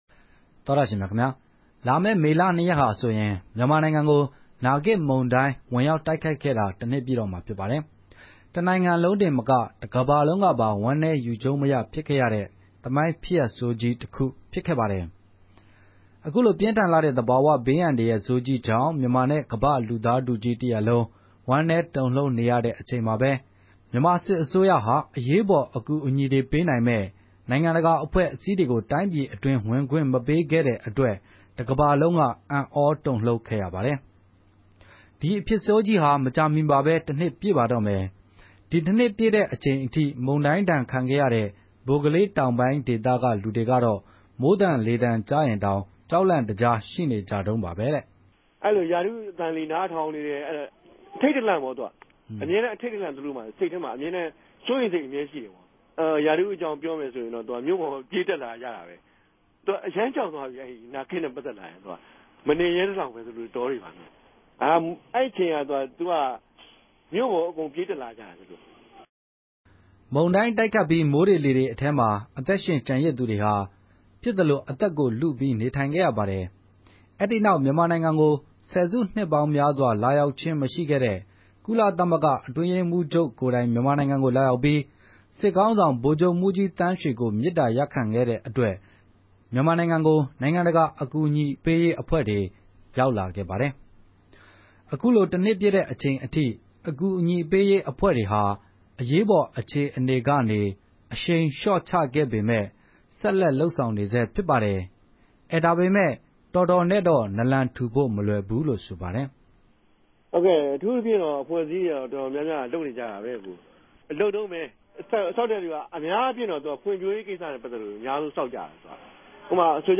ဘိုကလေးဒေသခံက အခုလိုေူပာပၝတယ်။
ဘိုကလေးေူမာက်ပိုင်းမြာ ဒေသခံတဦးက အခုလိုေူပာပၝတယ်။